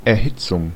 Ääntäminen
Synonyymit stoking Ääntäminen US : IPA : ['hiː.tɪŋ] Haettu sana löytyi näillä lähdekielillä: englanti Käännös Ääninäyte Substantiivit 1.